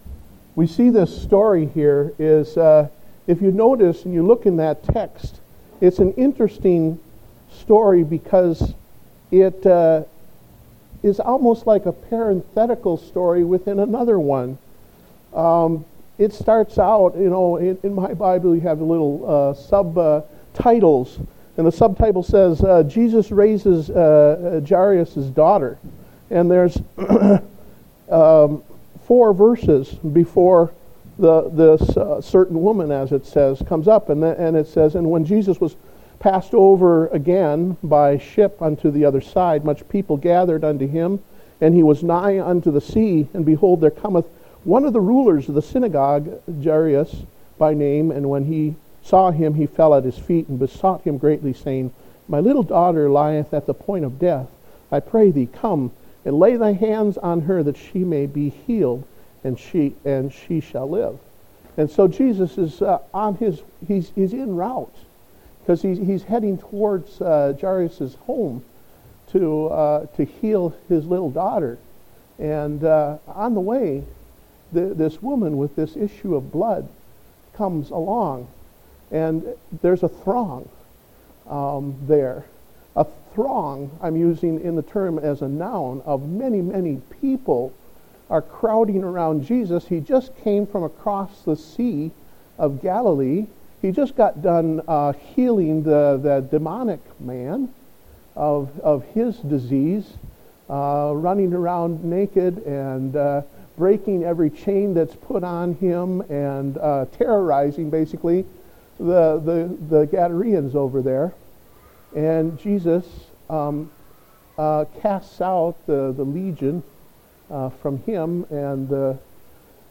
Date: November 29, 2015 (Adult Sunday School)